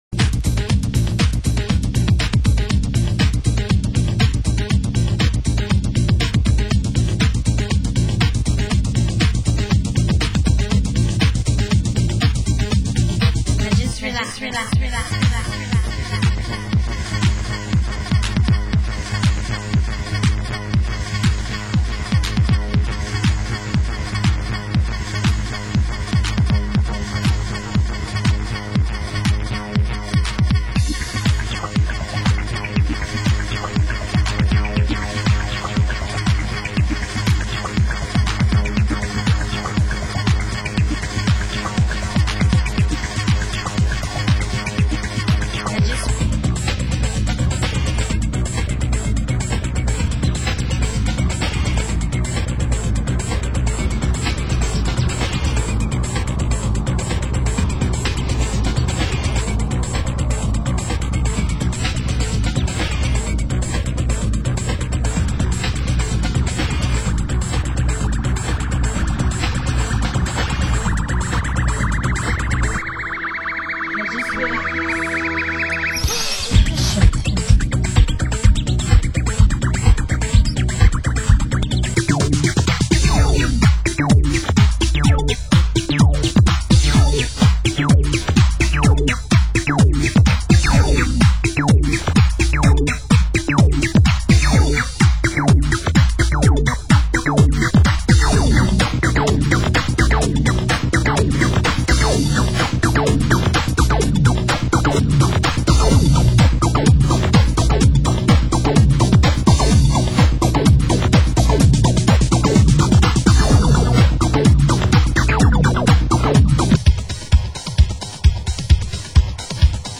Genre: US Techno